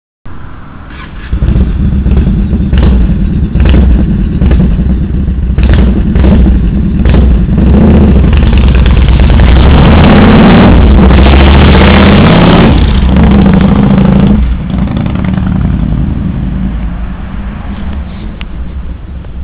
BUBマフラー音（走行） ノーマルマフラー音
録音状態があまり良くないので音が割れていますが、本物はなかなか良い感じです。
アイドリング時はうるさく感じますが、走り出すと和太鼓のようなサウンドを奏でてくれます。
muffler3.wav